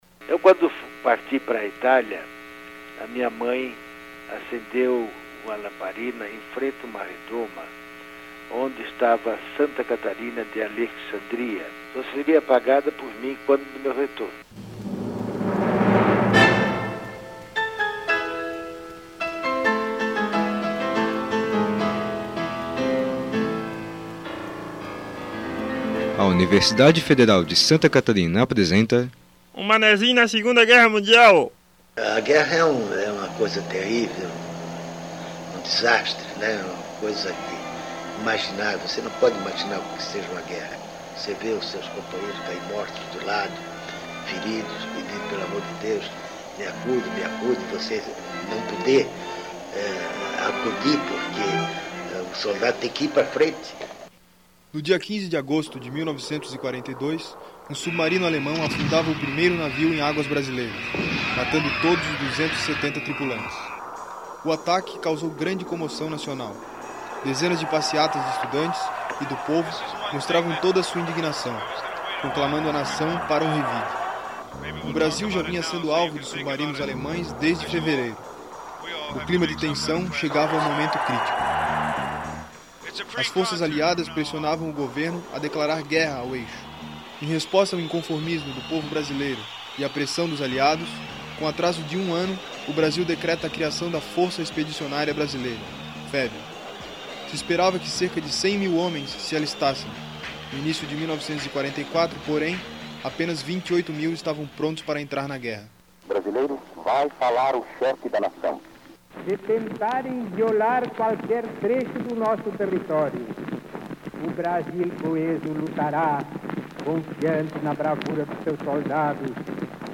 Documentário